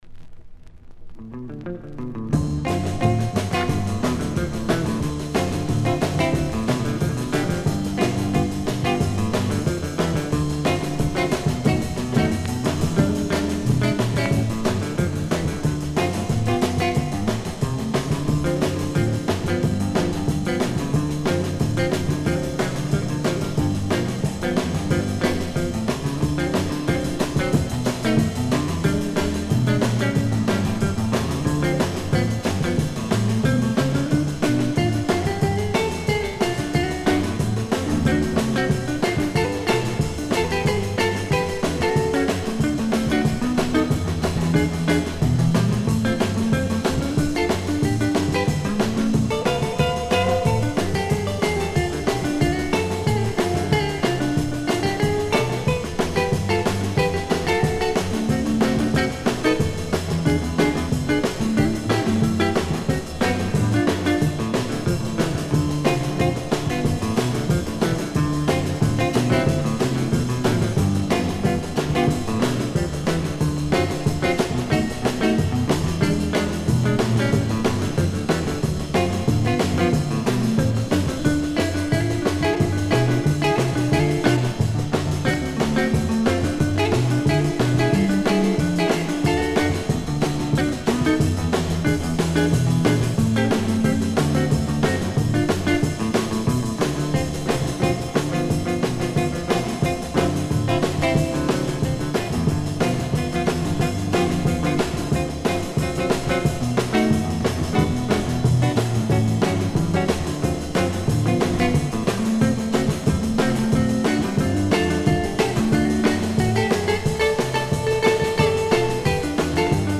Винил